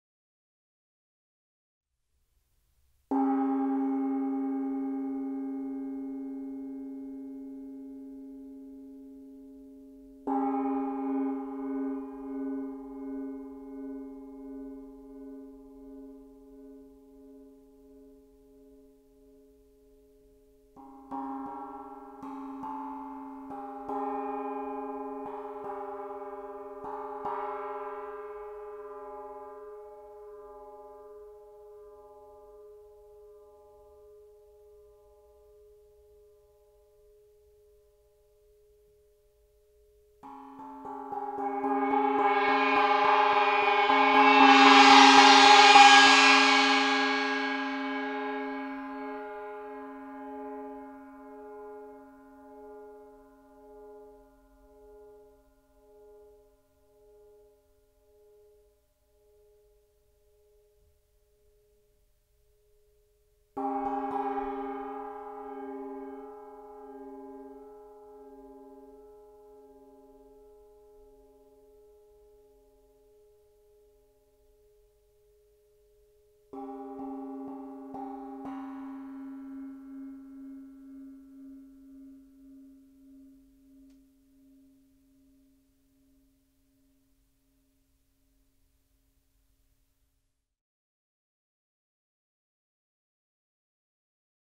Meinl Sonic Energy White Gong - 12" + Mallet (WG-12)